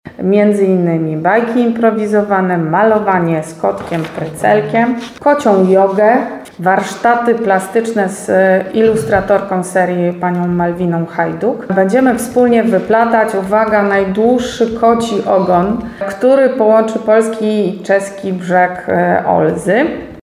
Szczególną atrakcją będzie sobotnie wyplatanie najdłuższego kociego ogona, który ma symbolicznie połączyć polski i czeski brzeg Olzy -mówi Gabriela Staszkiewicz, burmistrz Cieszyna.